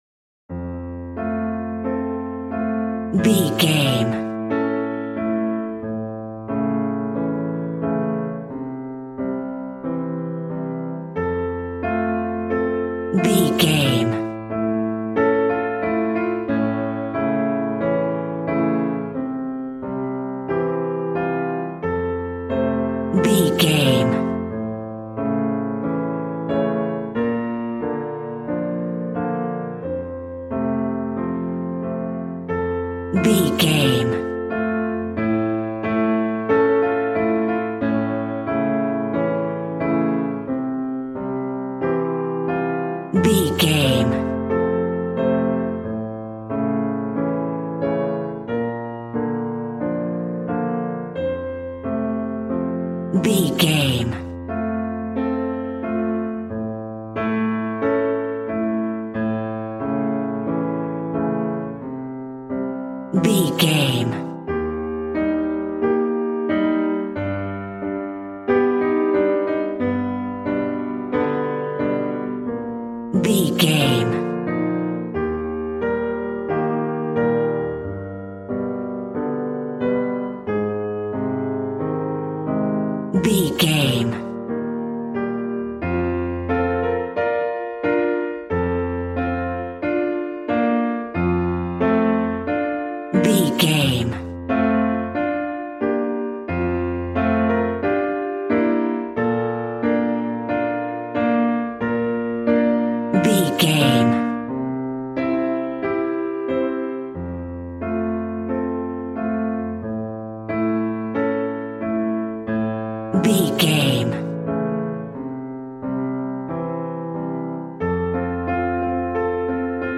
A warm and stunning piece of playful classical music.
Regal and romantic, a classy piece of classical music.
Aeolian/Minor
regal
piano
violin
strings